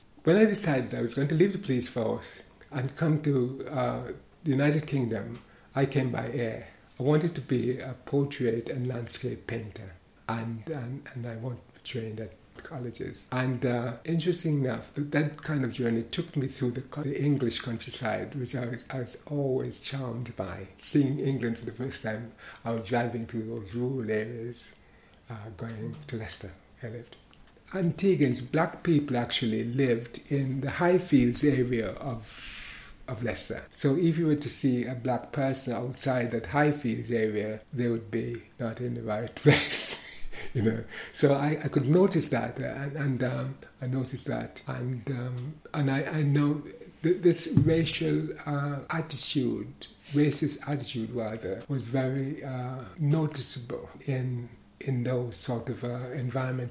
This oral history excerpt has been drawn from the three-year AHRC-funded project ‘The Windrush Scandal in a Transnational and Commonwealth Context’.